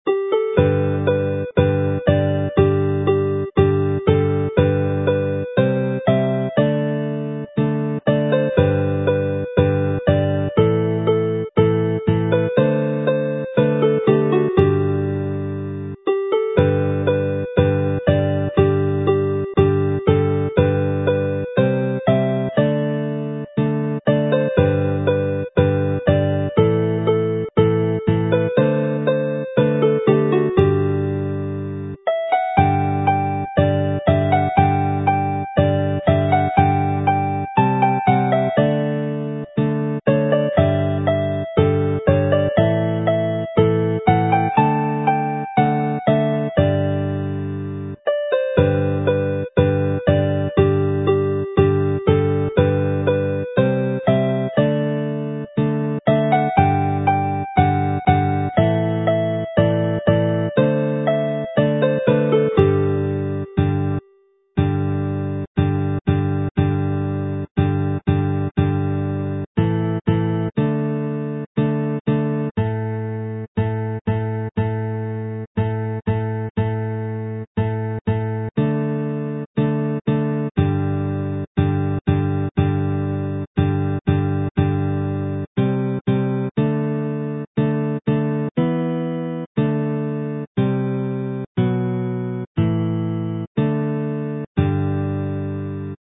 mp3 file as a polka, fast with chords mp3 file, slow with chords